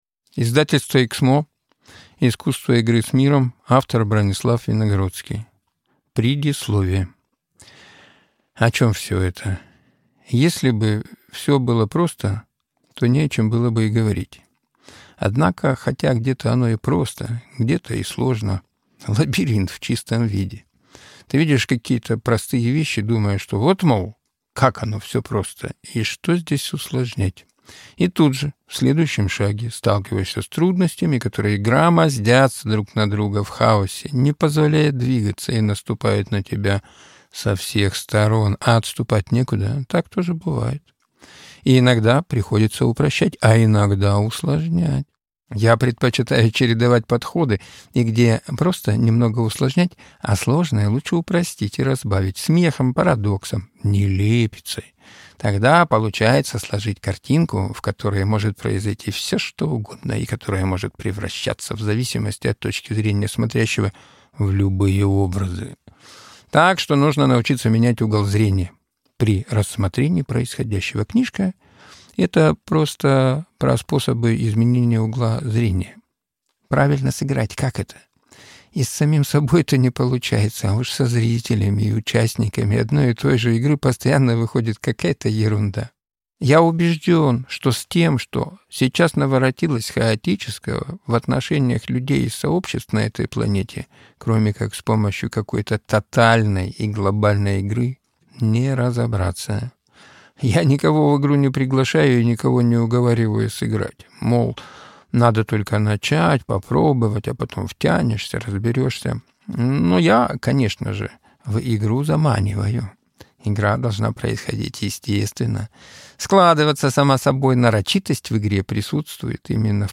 Аудиокнига Искусство игры с миром. Смысл победы в победе над смыслами | Библиотека аудиокниг